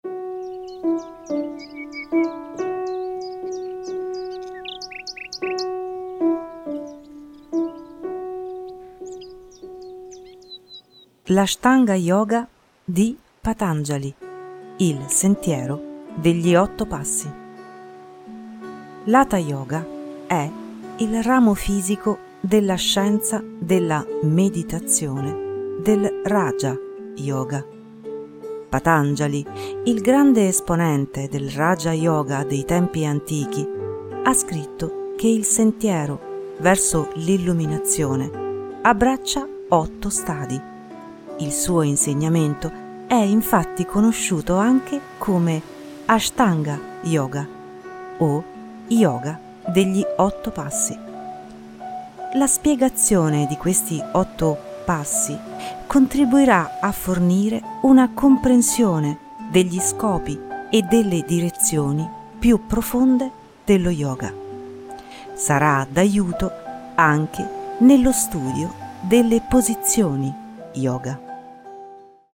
Gli otto passi di Patanjali - audiolibro - scaricabile
Registrato presso il Jyoti Studio.